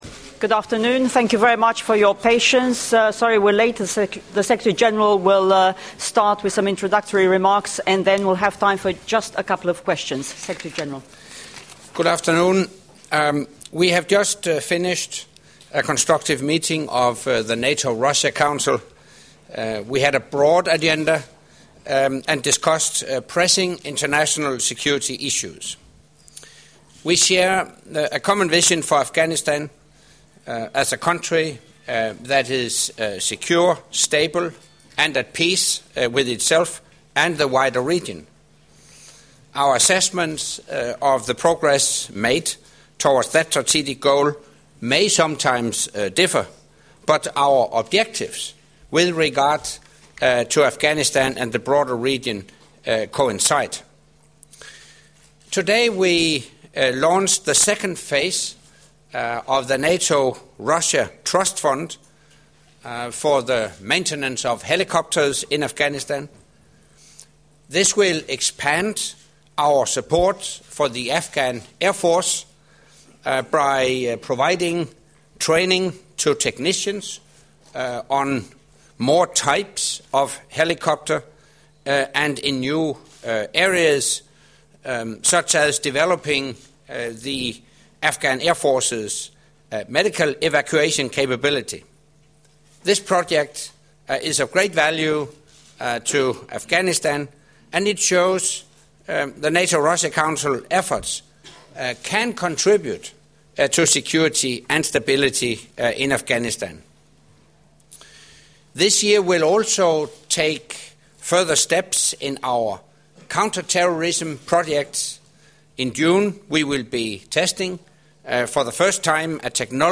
Press conference by NATO Secretary General Anders Fogh Rasmussen following the first North Atlantic Council Working Session and meeting of the NATO-Russia Council in Foreign Affairs Ministers session